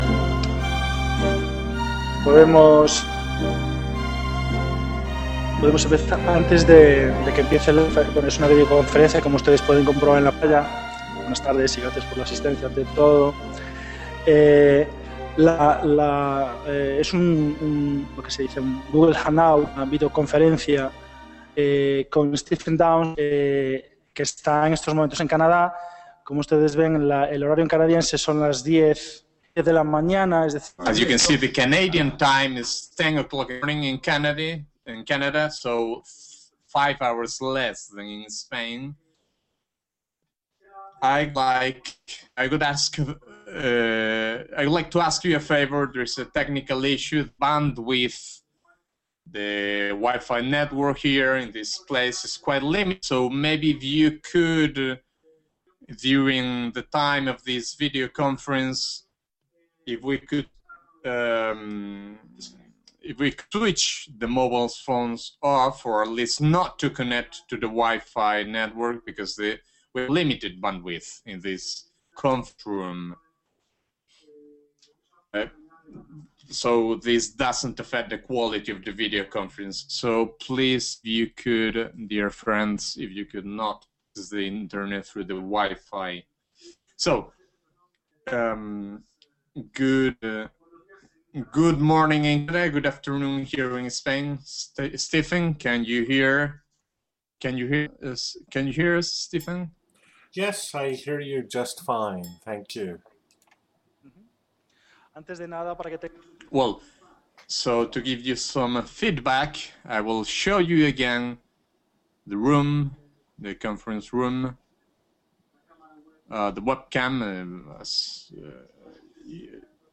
Keynote